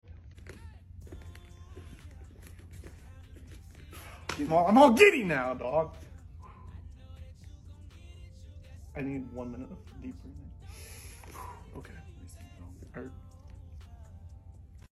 Warming up the vocal chords sound effects free download